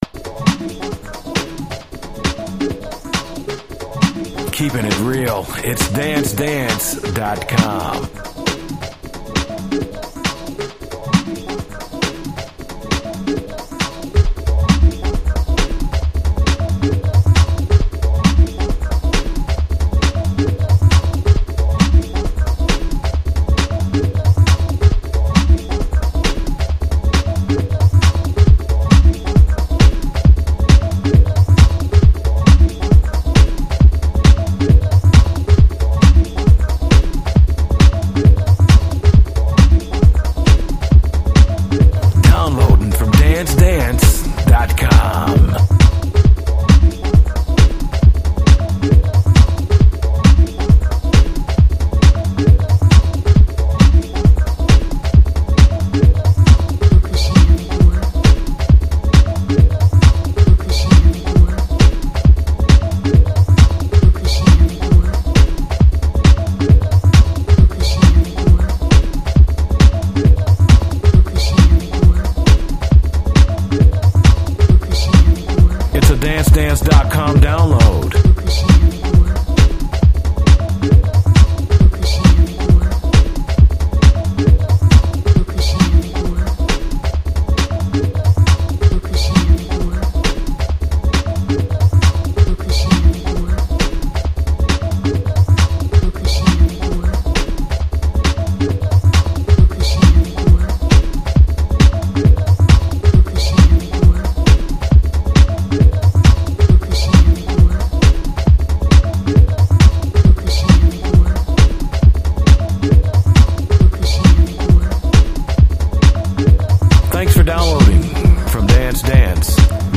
It’s was made when reason was released.